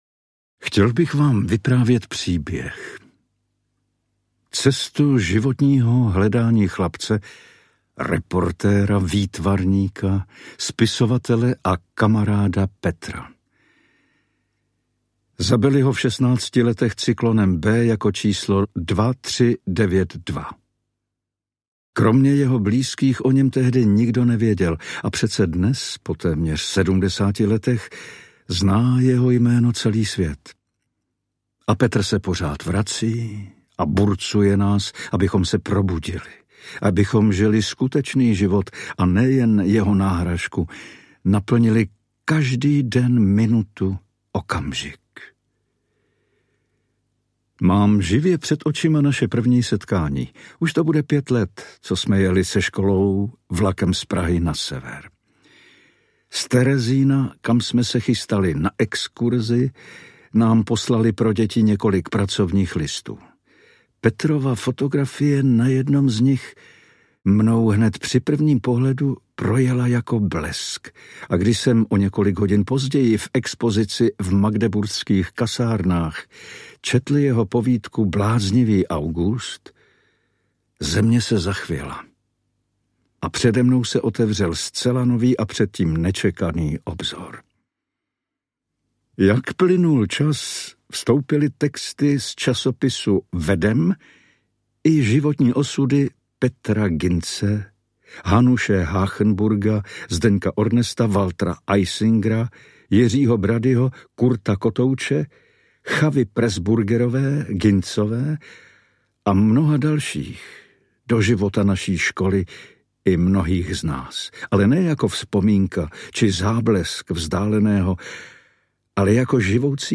Princ se žlutou hvězdou audiokniha
Ukázka z knihy
Vyrobilo studio Soundguru.